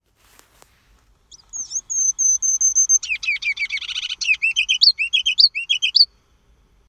Reyezuelo-matraquita.mp3
MKyrhkEaISB_Reyezuelo-matraquita.mp3